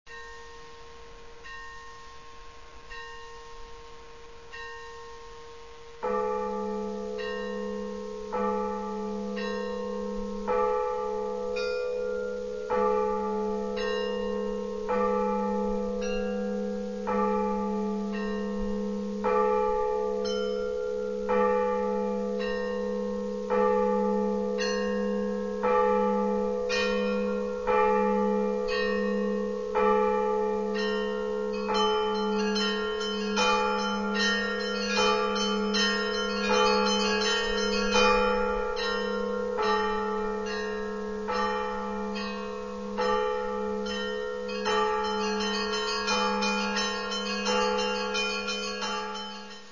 Дзвони дзвіниці на Ближніх печерах